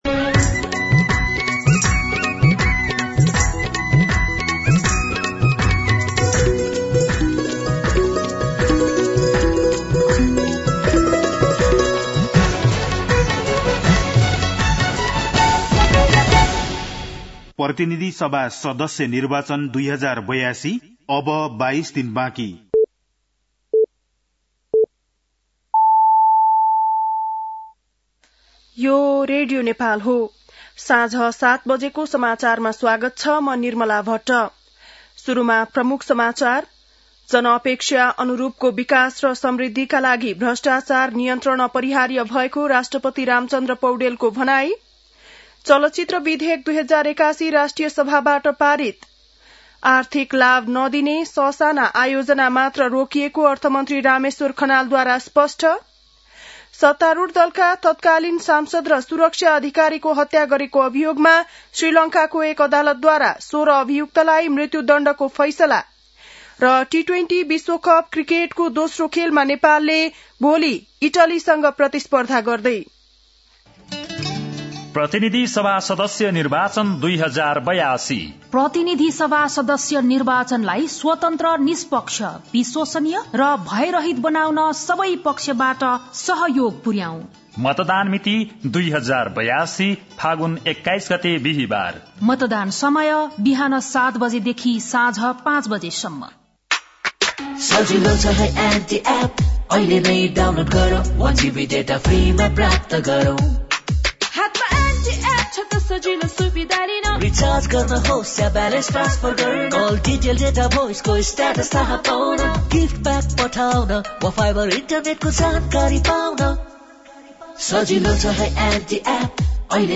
बेलुकी ७ बजेको नेपाली समाचार : २८ माघ , २०८२
7-pm-news-10-28.mp3